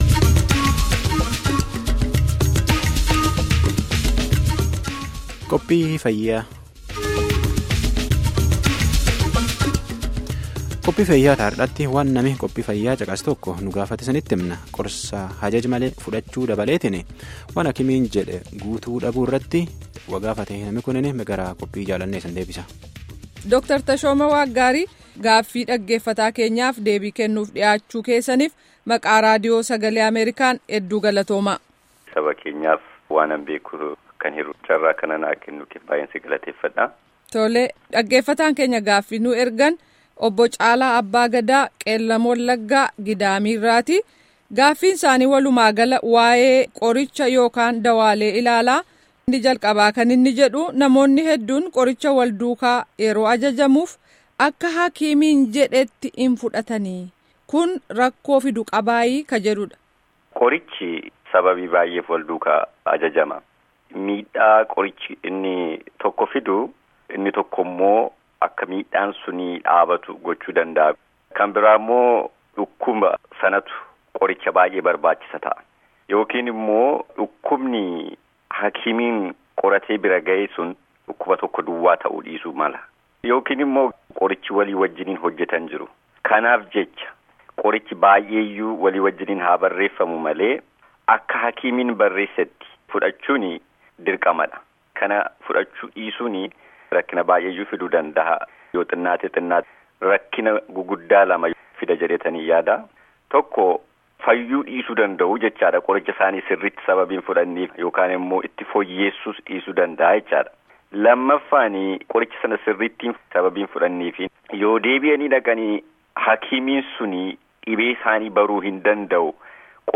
Gaaffii fi deebii guutuu armaan gaditti cqasaa